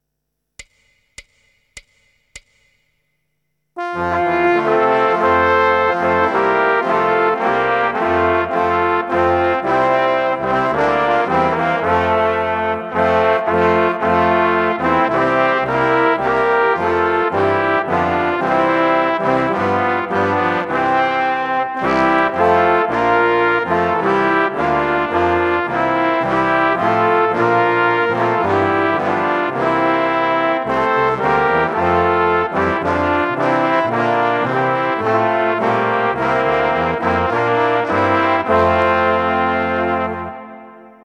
Besetzung: Blasorchester
Choräle/Grablieder/Hymnen/Trauermärsche/Straßenmärsche.